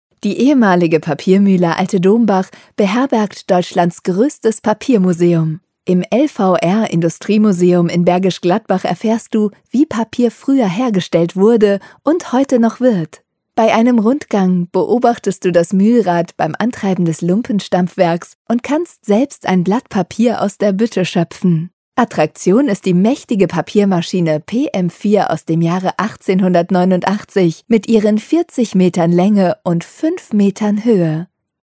audioguide-papiermuseum.mp3